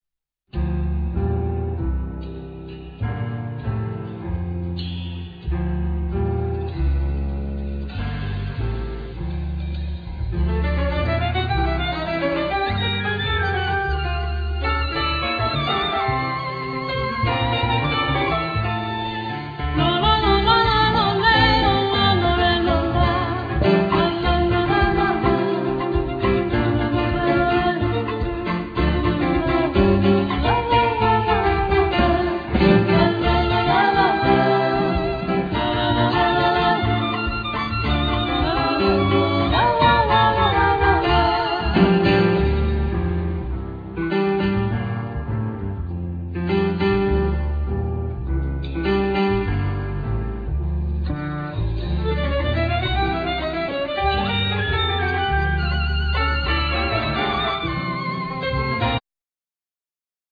Piano
Double Bass
Drums
Clarinet,Bass Clarinet
Bandoneon
Violin
Voice
Saxophone